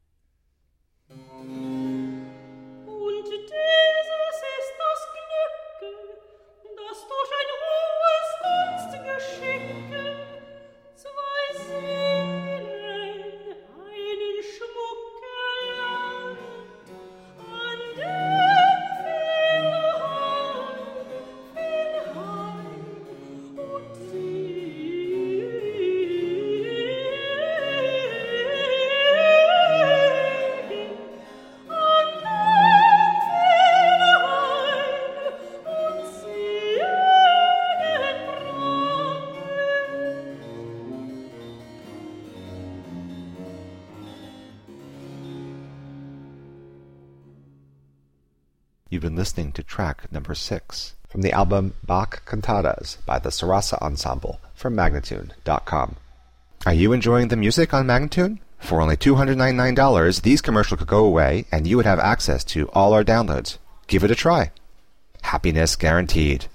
Lively, bright baroque .
mezzo-soprano